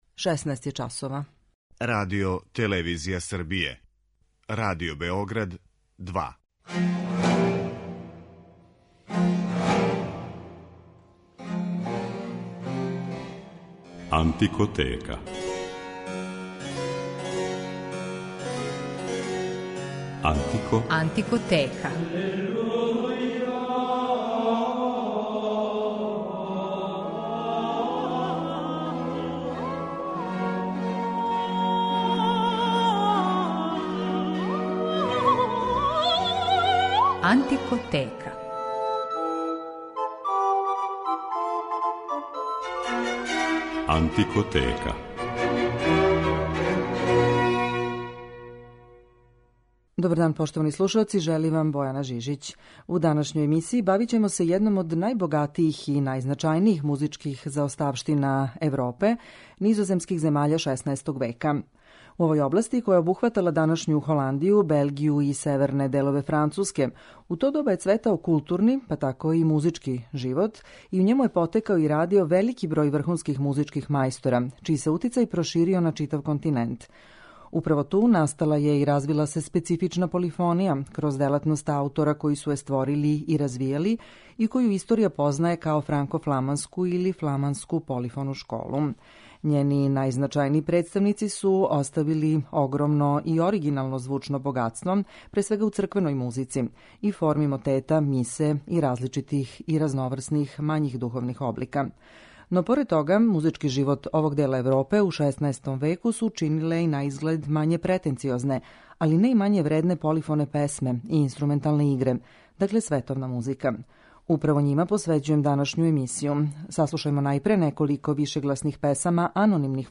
Песме и игре франко-фламанских мајстора 16. века